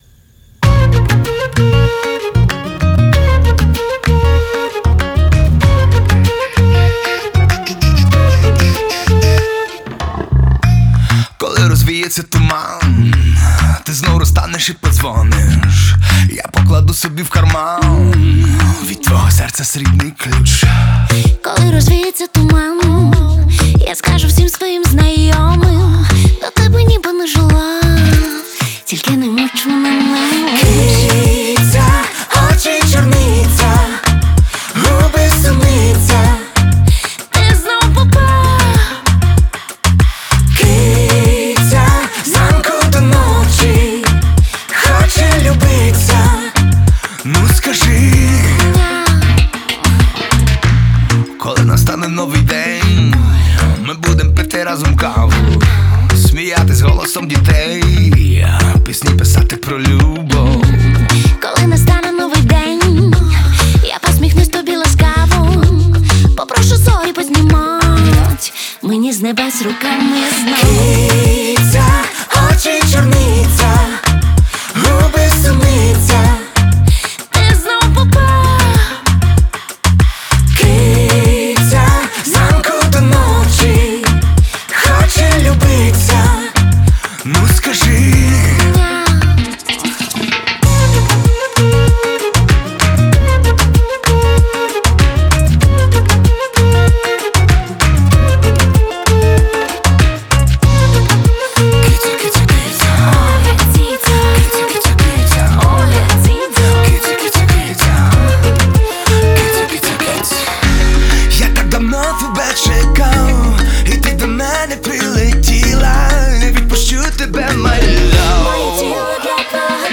отличается мелодичностью и запоминающимся ритмом